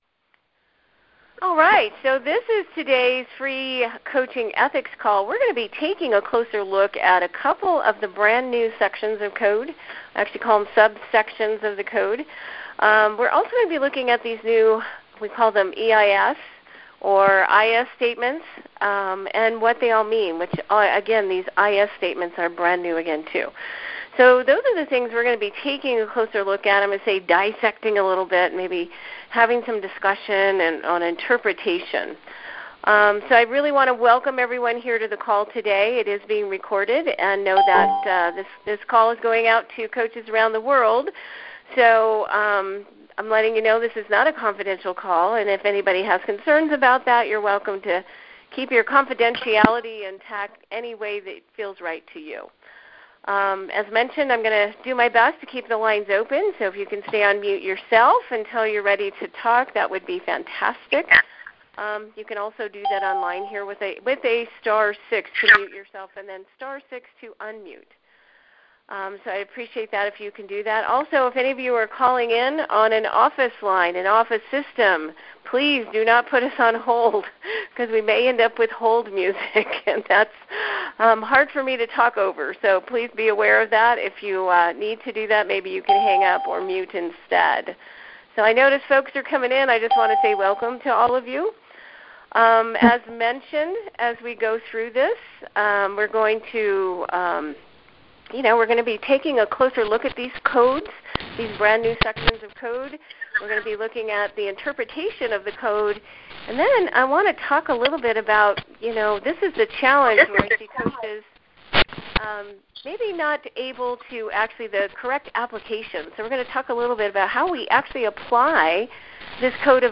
This free call will be an open discussion around the new code of ethics and what changes you may want to make in your operation as a coach.